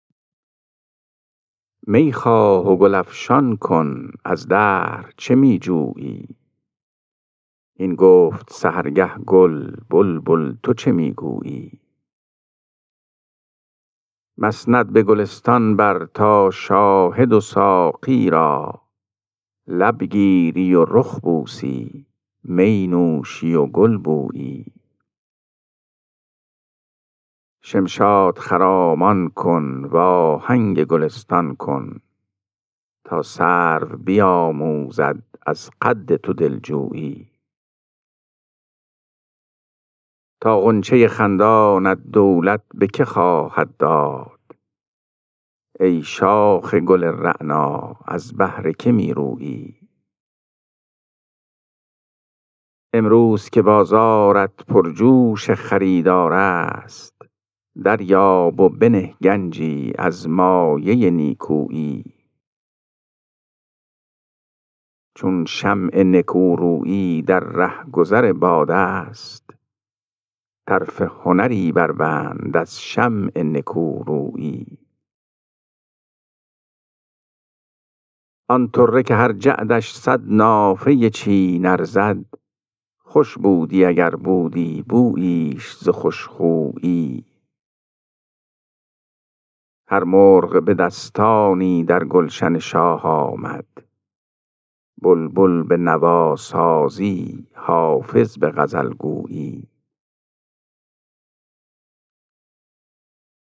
خوانش غزل شماره 495 دیوان حافظ